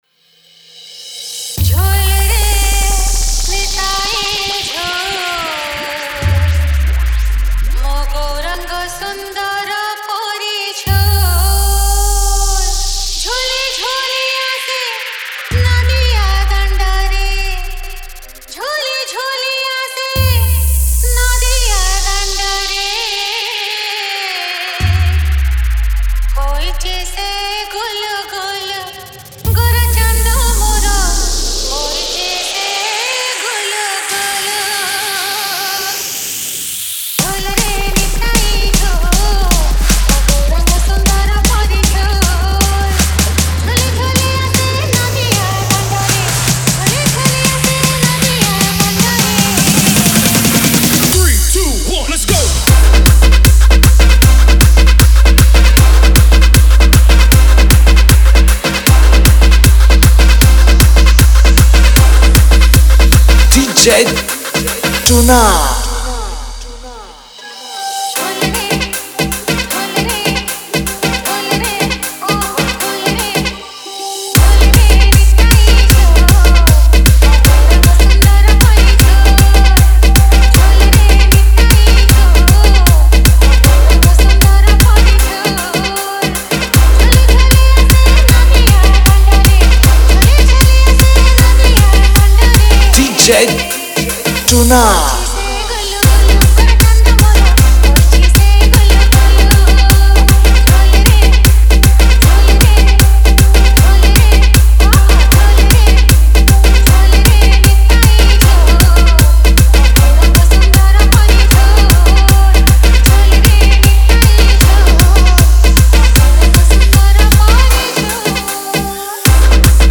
Bhajan Dj Song Collection 2022 Songs Download